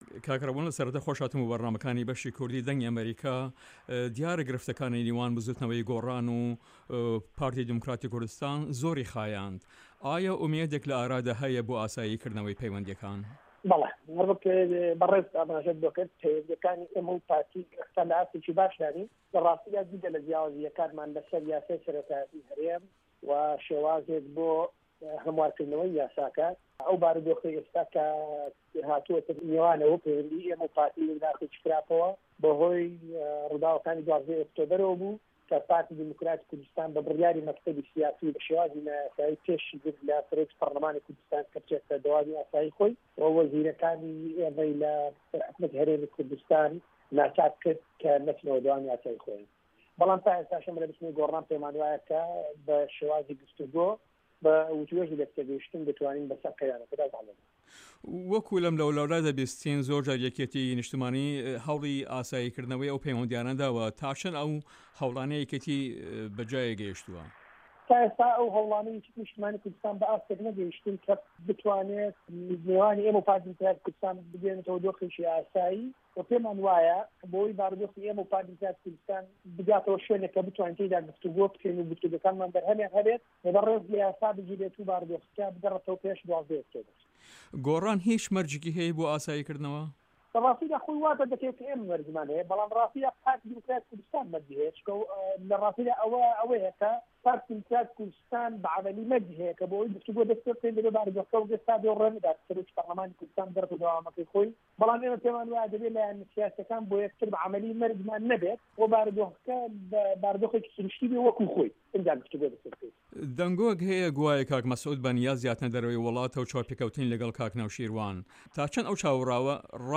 رابوون تەوفیق مەعروف ئەندامی پەرلەمانی کوردستان لەسەر لیستی بزوتنەوەی گۆڕان لە هەڤپەیڤینێکدا لەگەڵ بەشی کوردی دەنگی ئەمەریکا دەڵێت" لە راستیدا جگە لە جیاوازیەکانمان لەسەر یاسای سەرۆکایەتی هەرێم وە شێوازێک بۆ هەموارکردنەوەی یاساکە، ئەو بارودۆخەی ئێستا کە هاتووەتە نێوانەوە پەیوەندی ئێمە و پارتی خستووەتە قوناغێکی خراپەوە بە هۆێ رووداوەکانی 12 ئۆکتۆبەر، بەڵام تا ئێستاش ئێمە لە بزوتنەوەی گۆڕان پێیمان وایە کە بە شێوازی گفت و گۆ، بە وتو وێژی لە یەک تێگەیشتن بتوانین بەسەر قەیرانیەکەدا زاڵ بین.